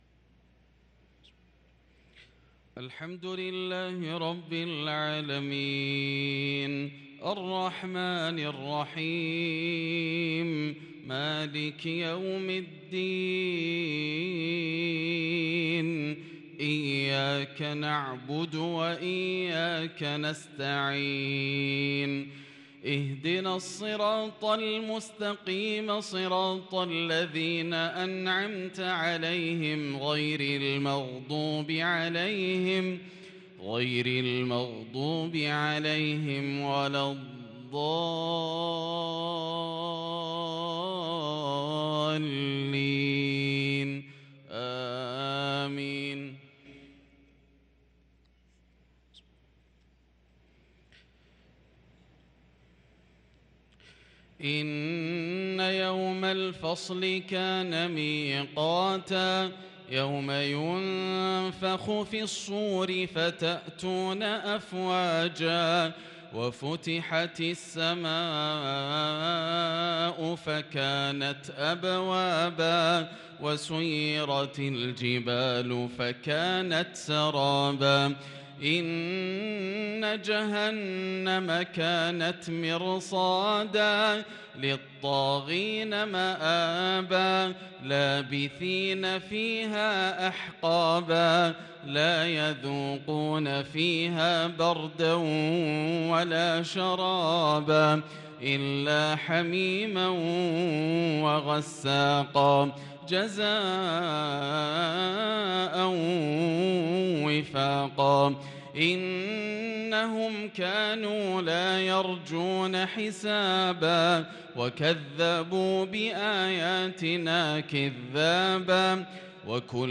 صلاة العشاء للقارئ ياسر الدوسري 29 صفر 1444 هـ